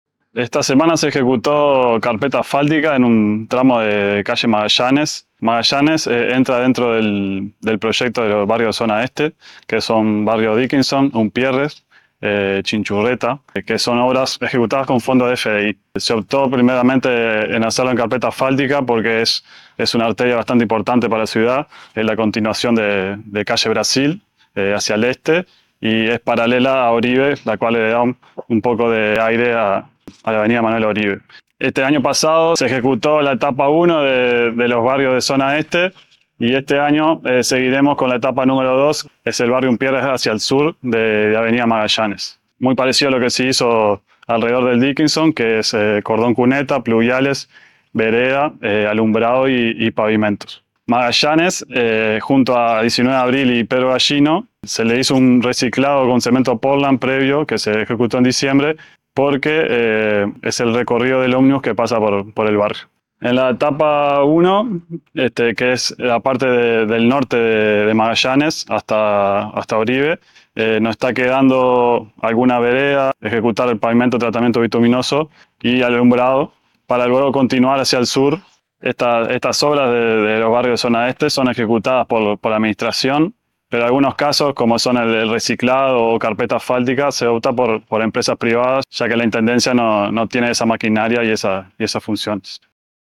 HABLA EL DIRECTOR
El director del departamento de Obras del Gobierno de Salto, Ing. Juan Manuel Texeira Núñez, informó que durante la semana pasada se ejecutaron trabajos de carpeta asfáltica en un tramo de calle Magallanes, en el marco del proyecto de infraestructura que se desarrolla en los barrios de la Zona Este de la ciudad.